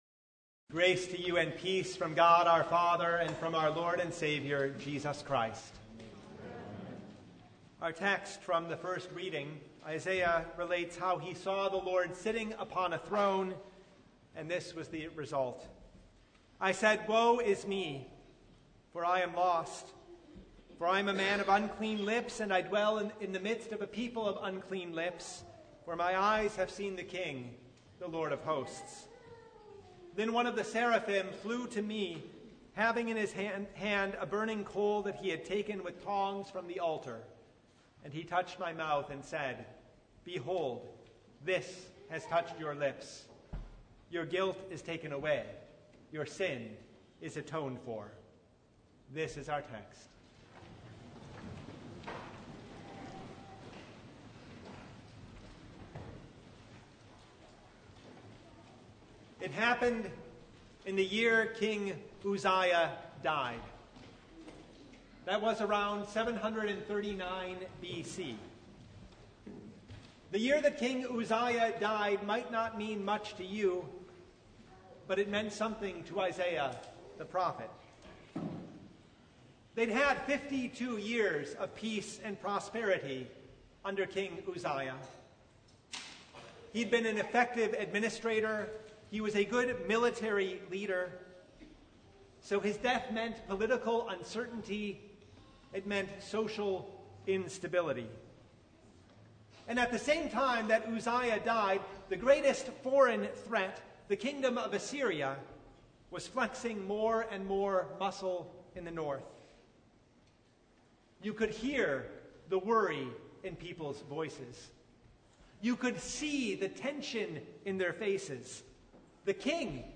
Isaiah 6:1-8 Service Type: The Feast of the Holy Trinity As with the Prophet Isaiah